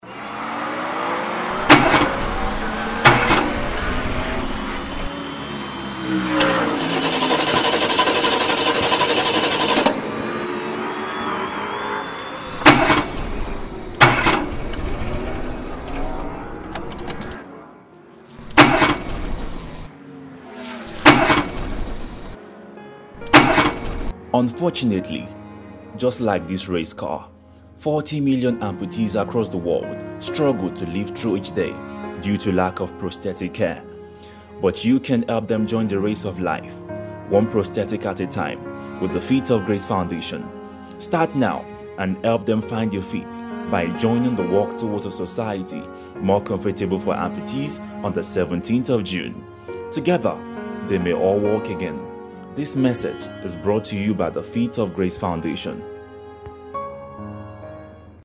PLAY RADIO AD